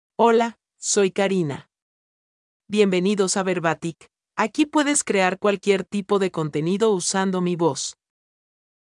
FemaleSpanish (Puerto Rico)
Karina — Female Spanish AI voice
Voice sample
Female
Karina delivers clear pronunciation with authentic Puerto Rico Spanish intonation, making your content sound professionally produced.